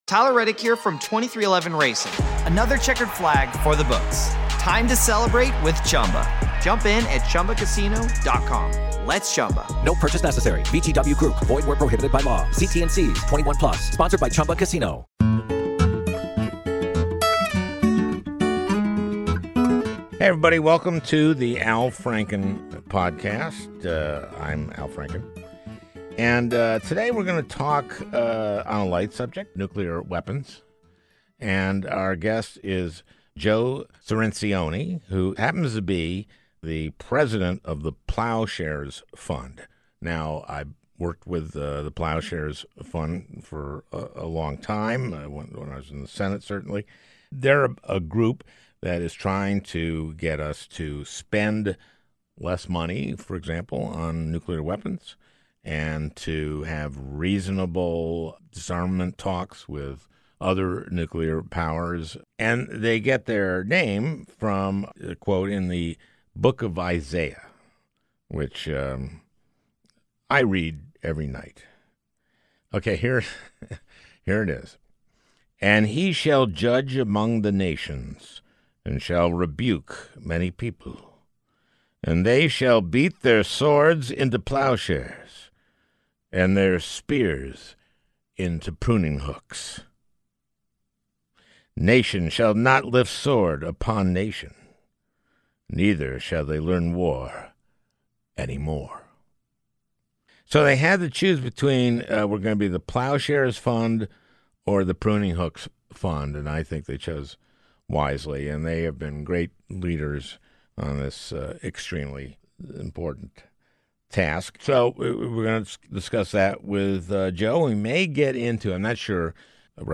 A Conversation with Joe Cirincione